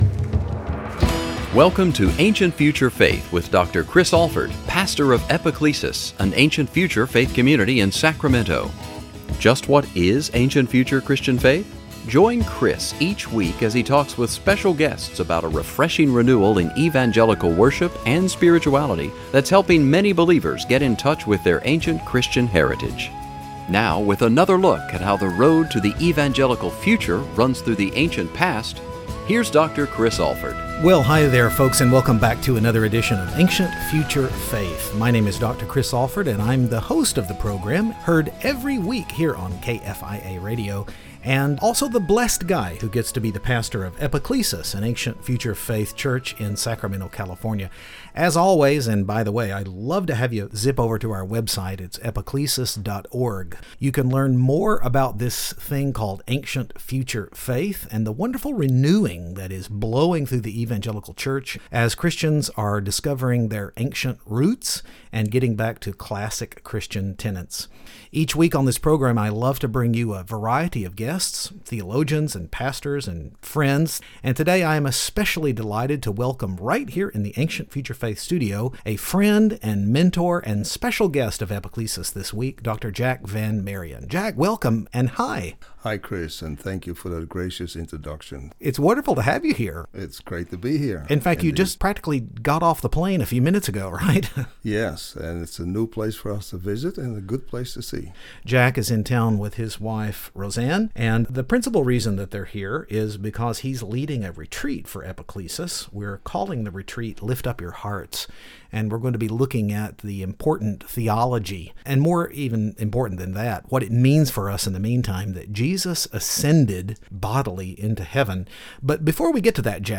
The miracle of Jesus’ bodily ascension into heaven gives us a beautiful picture of our future inheritance, but does his eternal Incarnation have meaning for kingdom living in the here and now? Join us for a great conversation.